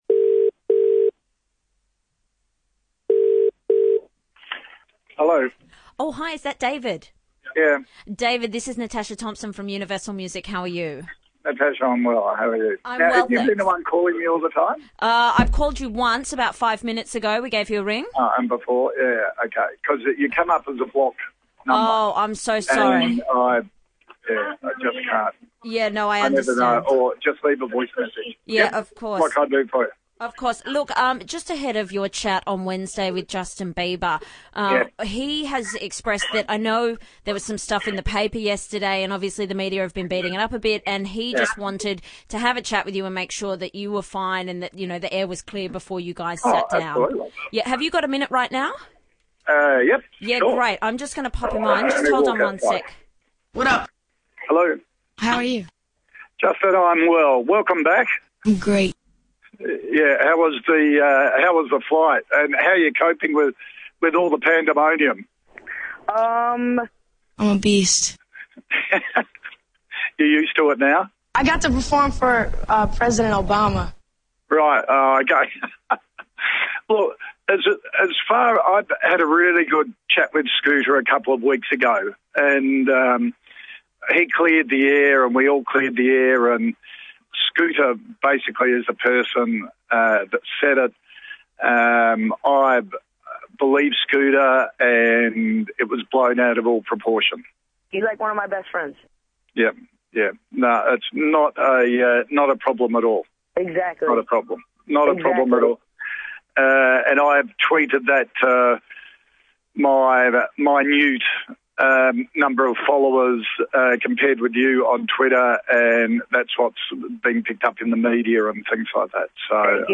So disorientating is the illness that Nova FM’s Fitzy and Wippa were able to engage in a long telephone conversation with Kochie using only a selection of Justin Bieber samples.